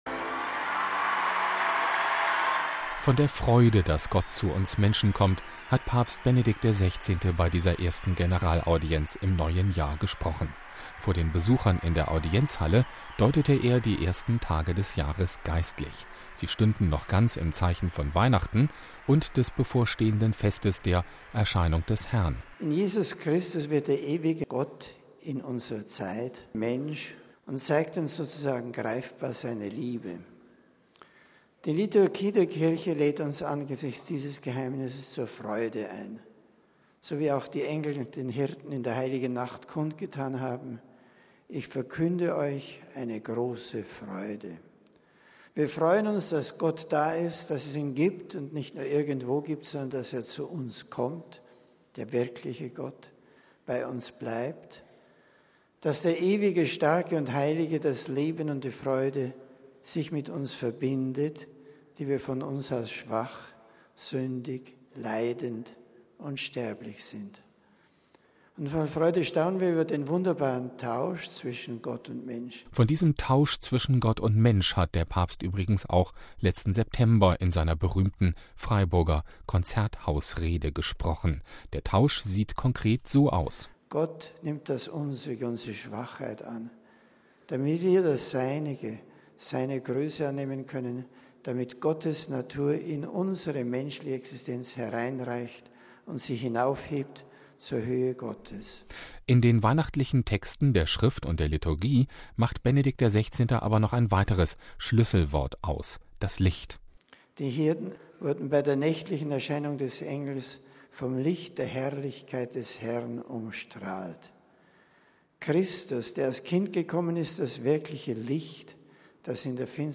Vor den etwa 9.000 Besuchern in der Audienzhalle deutete er die ersten Tage des Jahres geistlich: Sie stünden noch ganz im Zeichen von Weihnachten und weiter des bevorstehenden Festes der Erscheinung des Herrn.